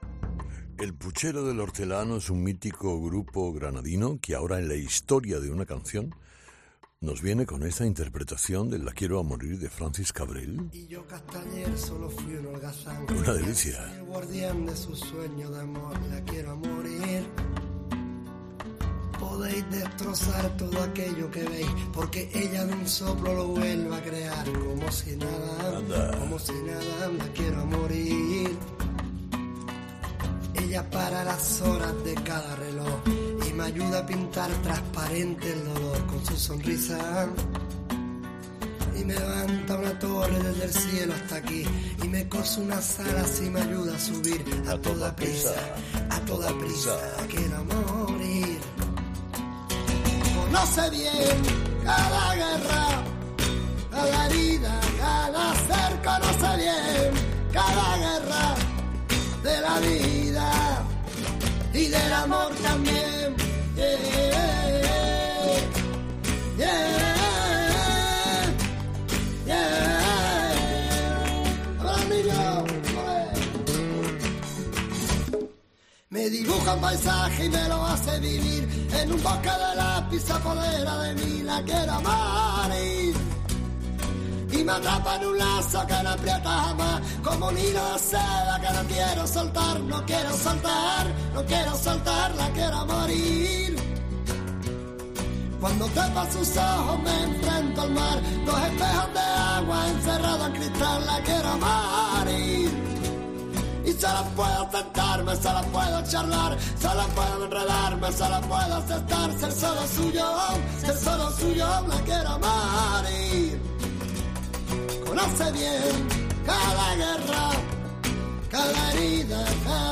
mítico grupo granadino